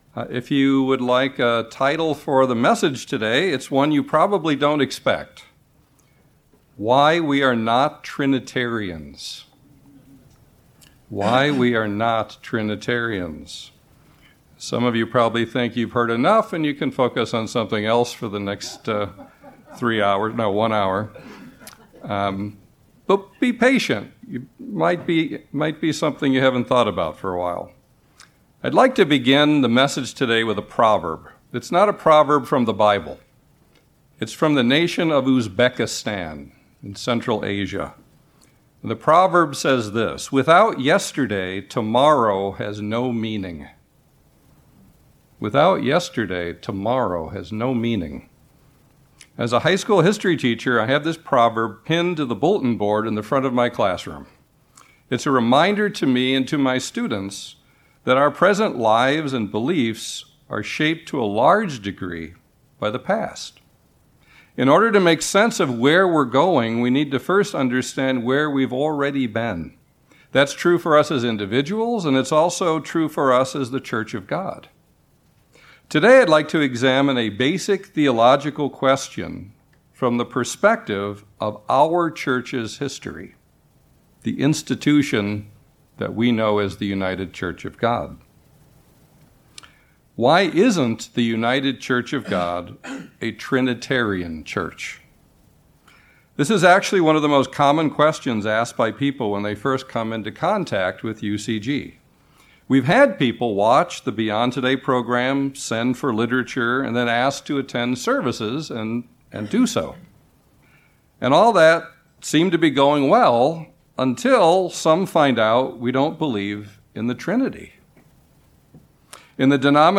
This message examines a basic theological question from the perspective of our Church’s history: Why isn’t the United Church of God a Trinitarian church? This sermon identifies three main reasons why we in the United Church of God are not Trinitarians.